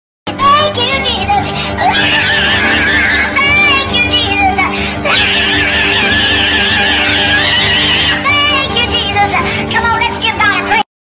haha funny talking tom go brrrr